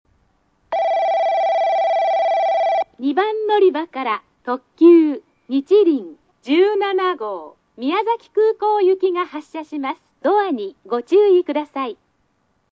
駅の音
スピーカー：ソノコラム
音質：Ｂ
停車中放送（にちりん・宮崎空港）　(57KB/11秒)